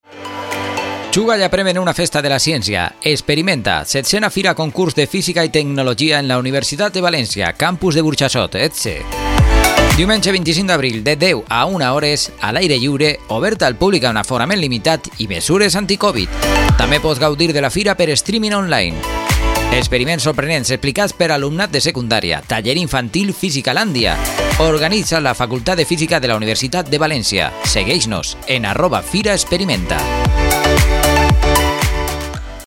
Falca de radio i spot de Experimenta 21